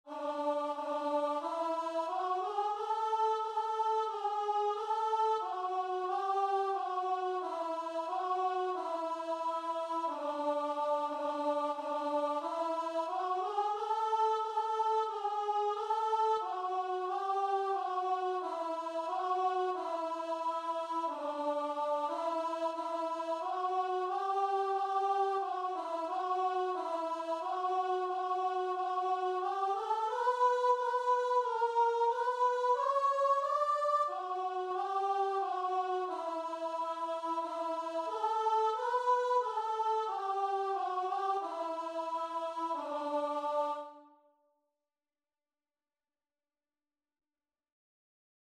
Christian
4/4 (View more 4/4 Music)